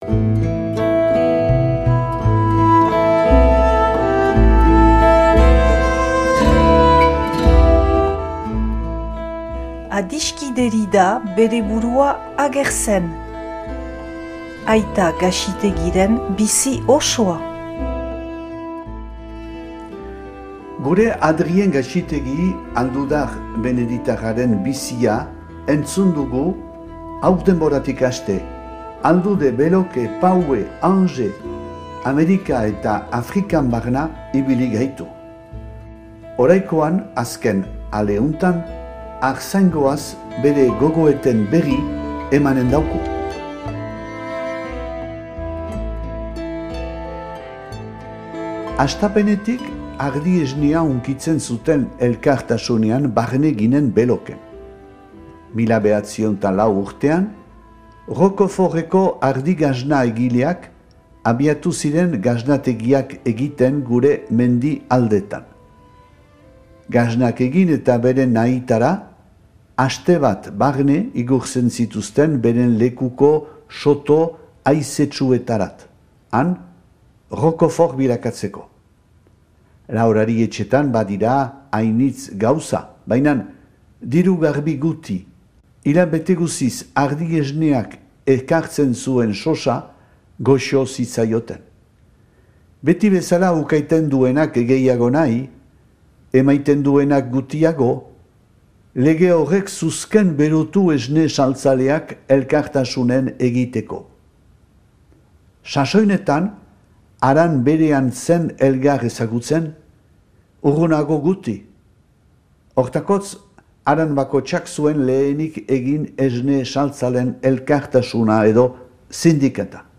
irakurketa bat dauzuegu eskaintzen